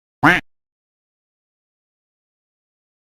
quack.mp3